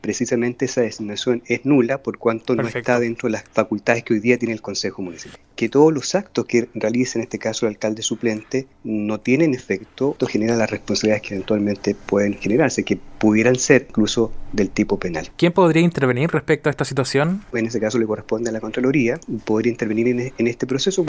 En entrevista con La Radio